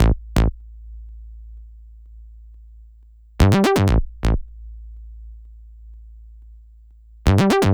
TI124BASS1-R.wav